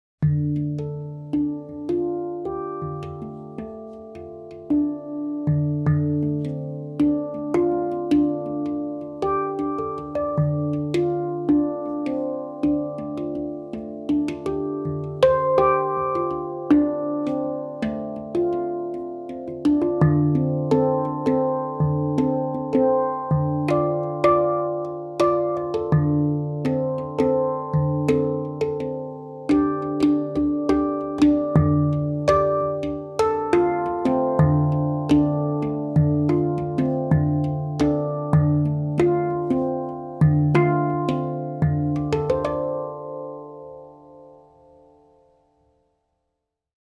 Moon II Handpan i D-moll (Ø 55 cm) byr på en varm og melankolsk klang som inviterer til intuitivt og meditativt spill.
Instrumentet er laget av rustfritt stål, som gir klar tone, lang sustain og balanserte overtoner.
• Stemning: D-moll – harmonisk, melankolsk og meditativ.
• Klar tone med lang sustain.
D3, A3, C4, D4, F4, G4, A4, C5, D5